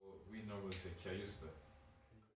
Not wanting to muddy the waters, but he said Ka-yus-ta! I recorded it.
Bit faint as I was the other side of the room, but felt it was important to get an audio record.
He said it's a Swedish pronunciation of a Haitian, therefore presumably originally French, name.